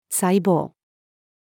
細胞-female.mp3